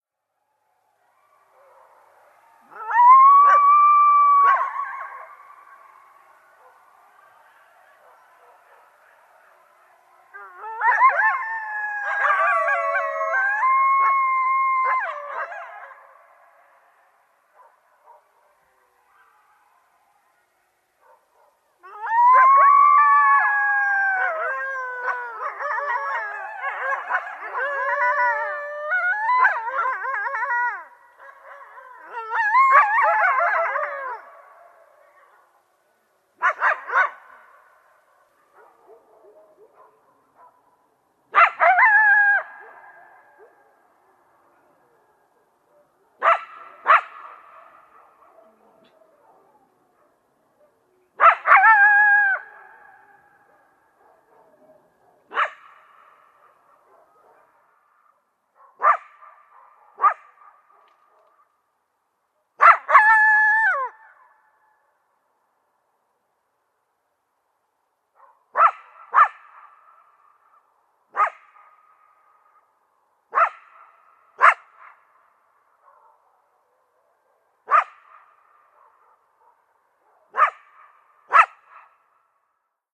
Звуки записаны в естественной среде обитания и идеально подходят для ознакомления с фауной, создания атмосферы в проектах или использования в качестве звуковых эффектов.
Стая койотов в дикой природе громко воет